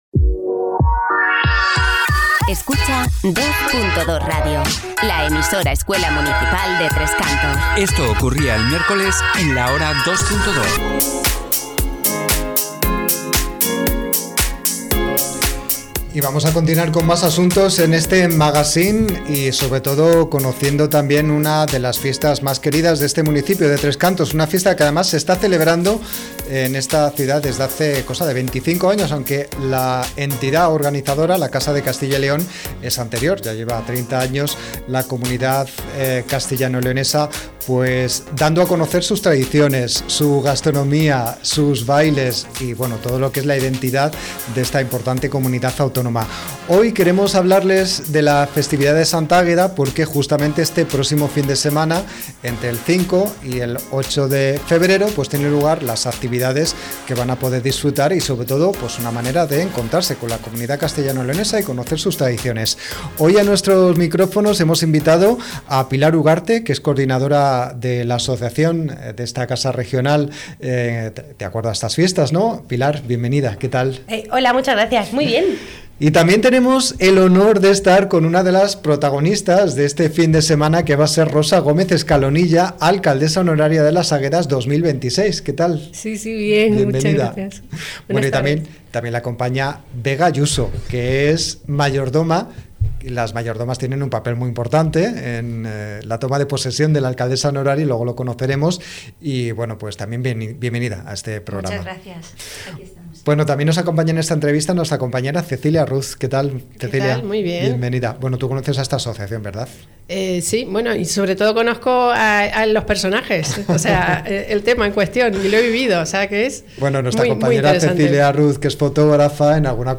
ENTREVISTA-FIESTA-DE-LAS-AGUEDAS.mp3